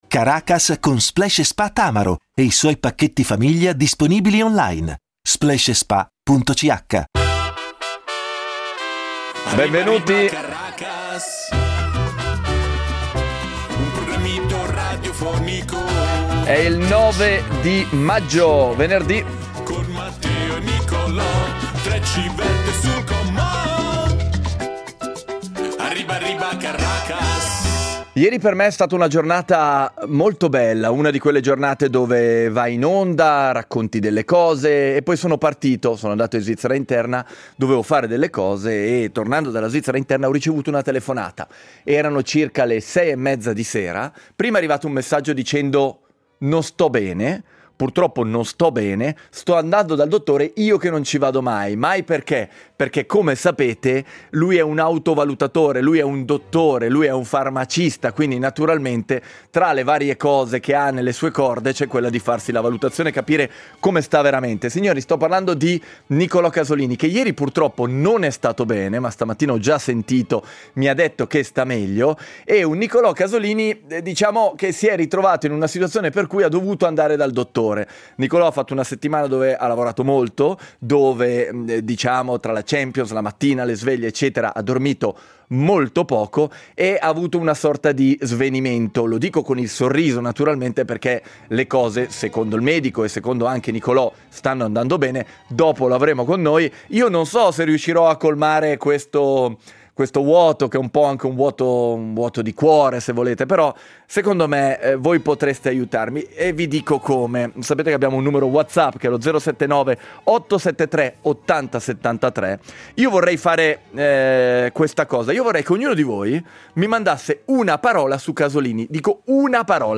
C’è silenzio, c’è tranquillità.